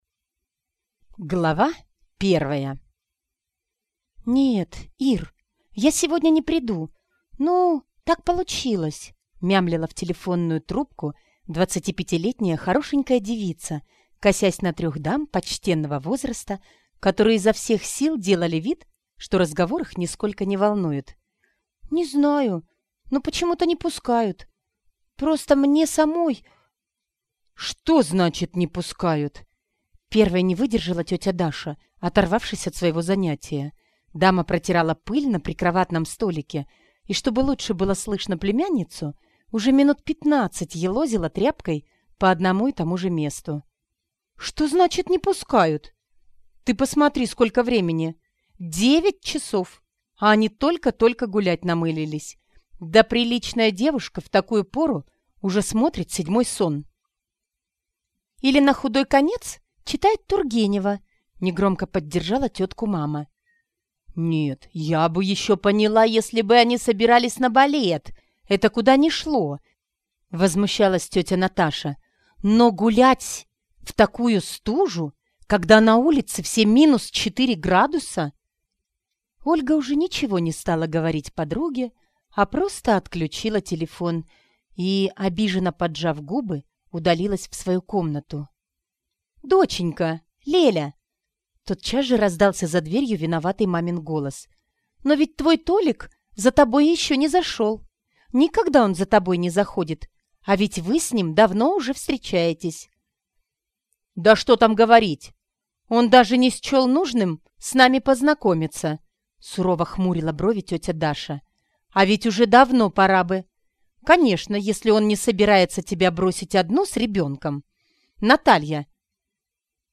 Aудиокнига Курсы кройки и житья